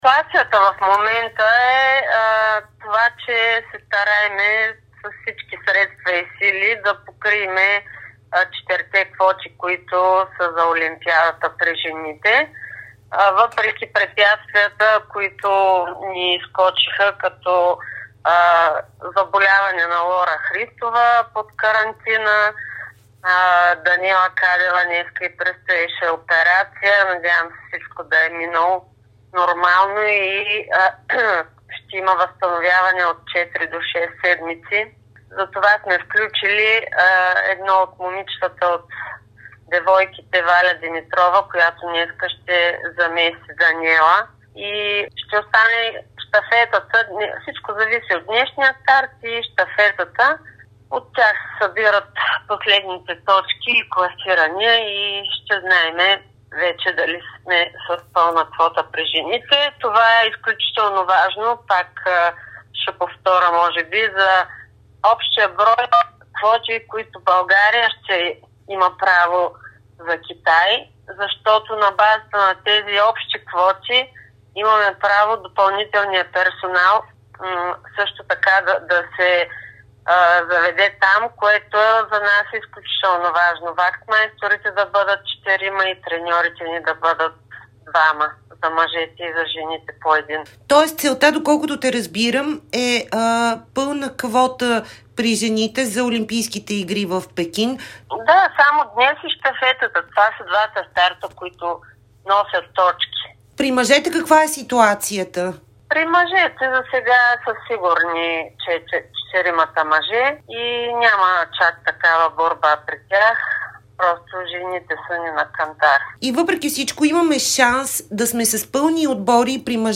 Чуйте интервюто с Катя Дафовска за това, което предстои на българския биатлон.